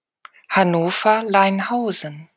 Audioaufzeichnung der Aussprache eines Begriffs. Sprache InfoField Deutsch Transkription InfoField Hannover Leinhausen Datum 9.